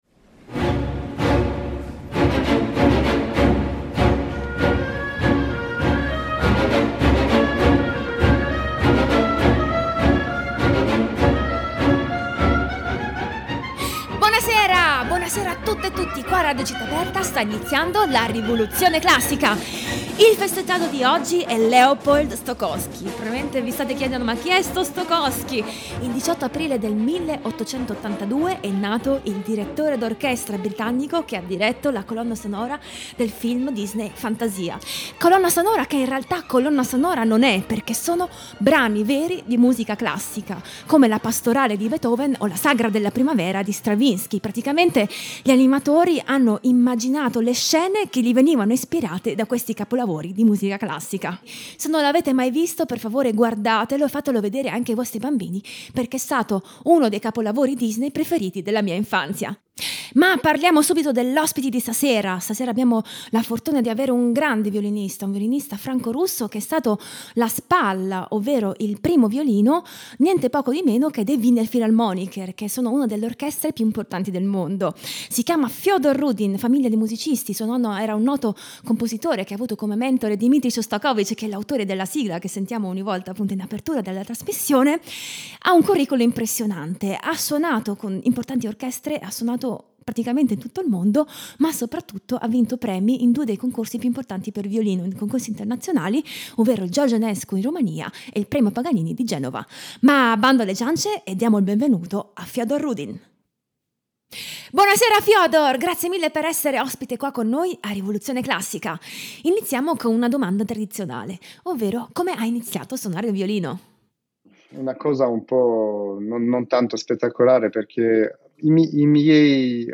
violino
clavicembalo
Ospite di questa puntata il violinista
pianoforte